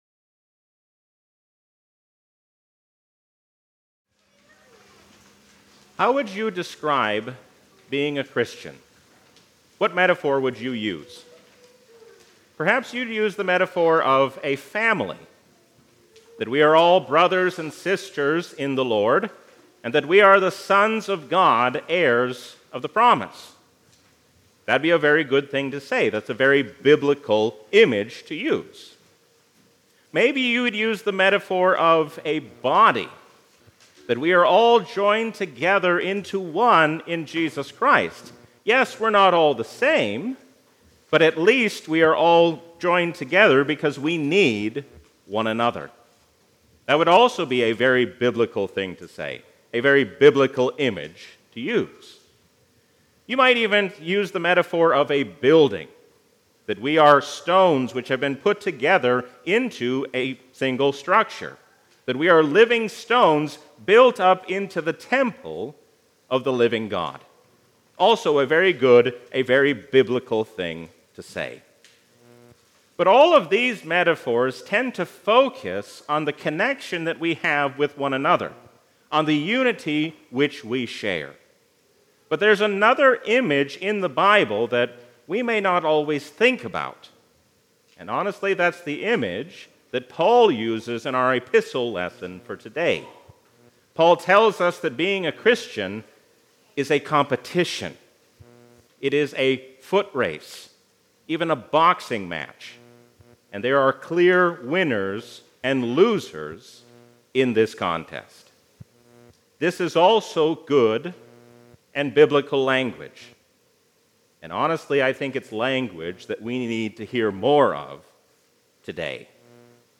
A sermon from the season "Gesimatide 2022."